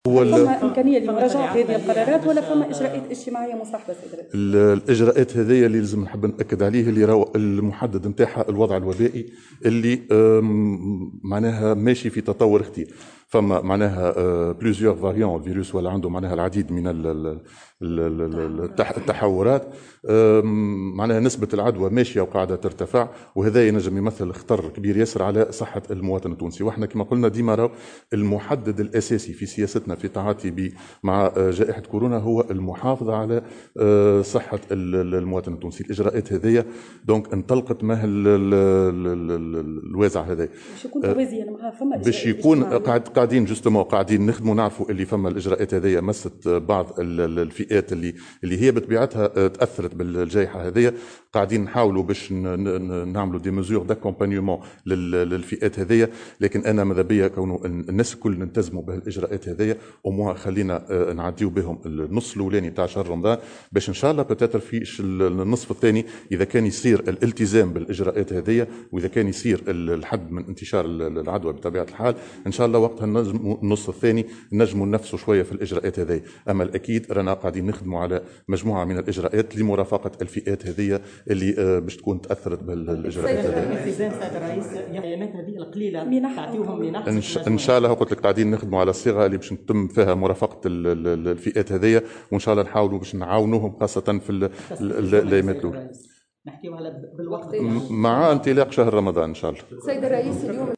أكد رئيس الحكومة هشام مشيشي اليوم الخميس في تصريح لموفدة "الجوهرة اف أم" أن خطورة الوضع الوبائي دفعت الى اتخاذ الاجراءات التي اعلنتها حكومته أمس.